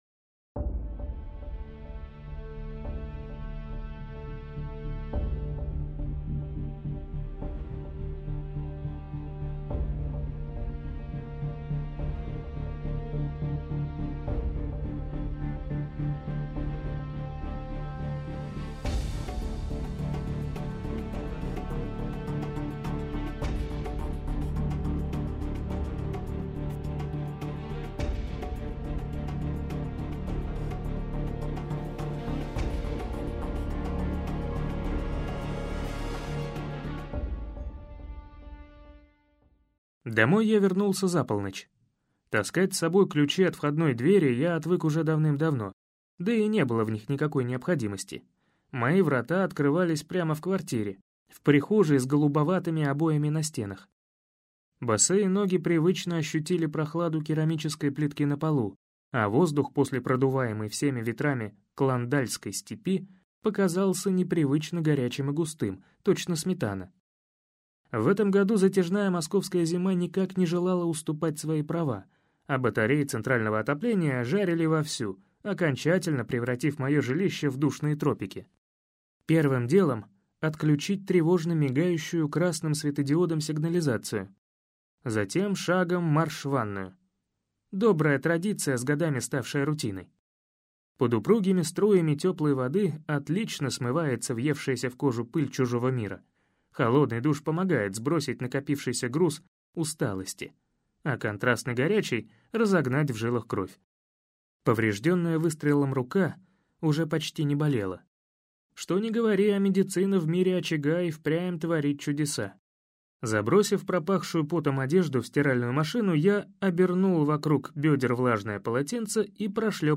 Прослушать фрагмент аудиокниги Очаг Валентин Лукьяненко Произведений: 1 Скачать бесплатно книгу Скачать в MP3 Вы скачиваете фрагмент книги, предоставленный издательством